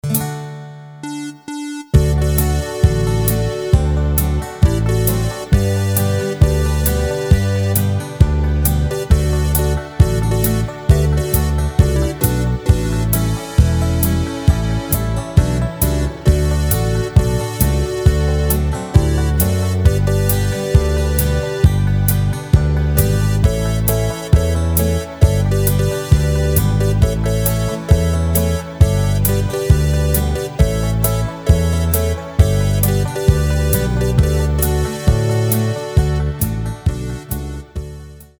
Rubrika: Pop, rock, beat
Nejnovější MP3 podklady